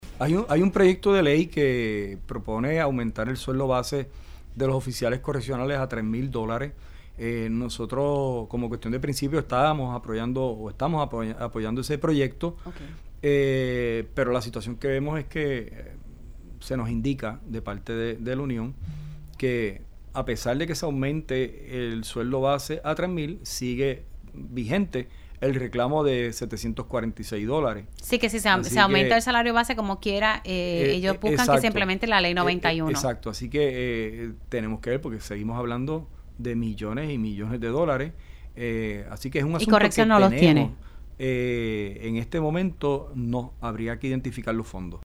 El secretario de Corrección, Francisco Quiñones reveló en Pegaos en la Mañana que el Departamento de Corrección y Rehabilitación (DCR) no cuenta con suficientes fondos para poder otorgar el aumento retroactivo- así como lo estipula la ley 91 del 2022 – a los oficiales correccionales.